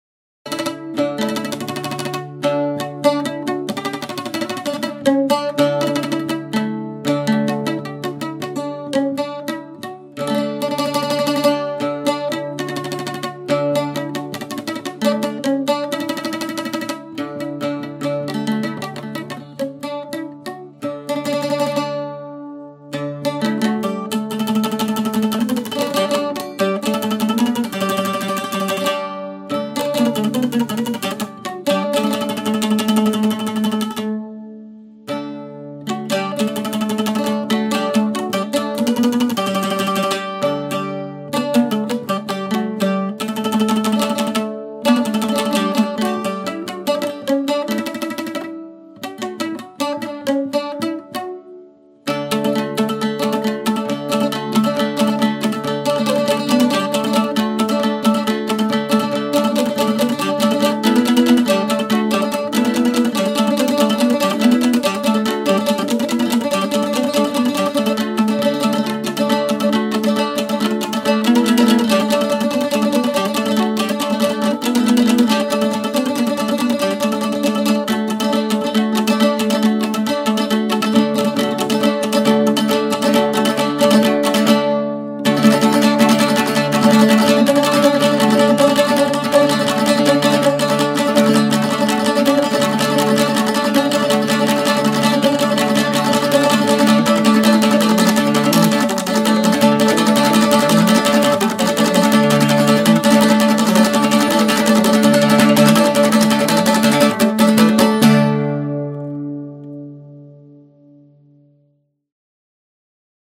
Traditional Moldova Instrument
Audio file of the Cobza
Sound-of-the-instrument-Cobza.mp3